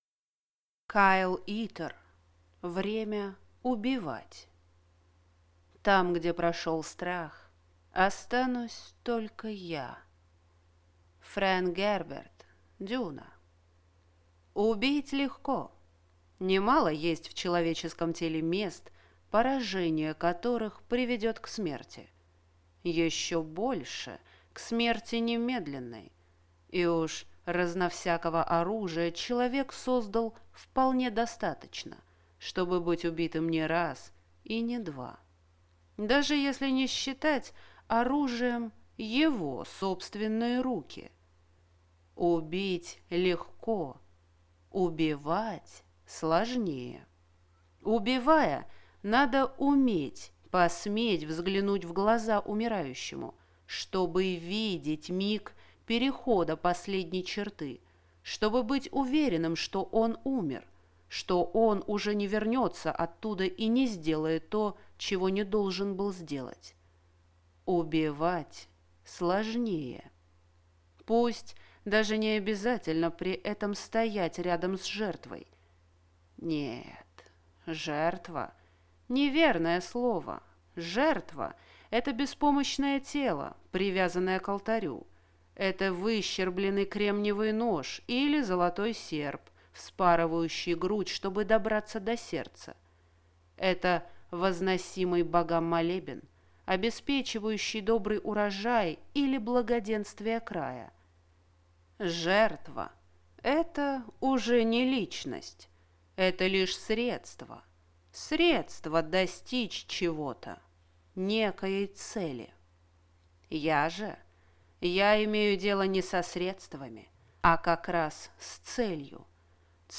Аудиокнига Время убивать | Библиотека аудиокниг
Прослушать и бесплатно скачать фрагмент аудиокниги